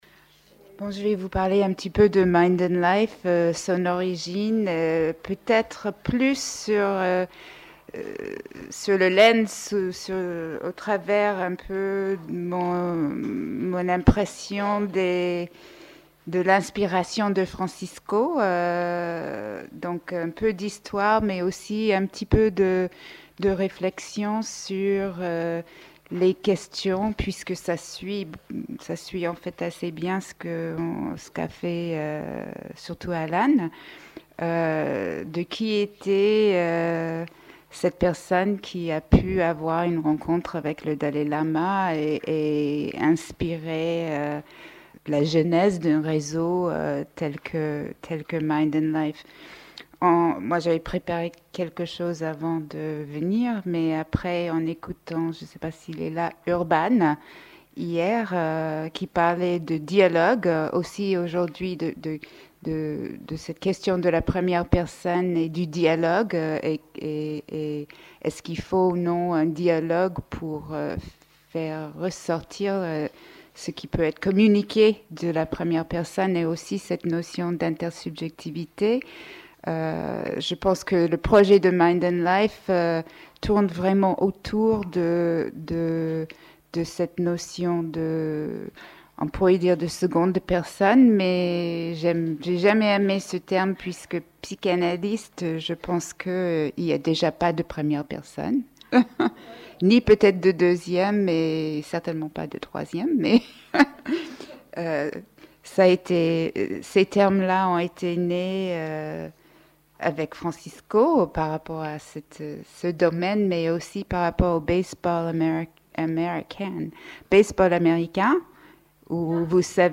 Cette conférence examine les motivations de Francisco Varela pour fonder Mind and Life ; celles-ci coïncident avec une des idées fondamentales de sa pensée : celle de faire dialoguer plusieurs domaines — la science occidentale, la phénoménologie et le bouddhisme — afin d'élargir le champ d'une compréhension plus ample de l'esprit et de la conscience.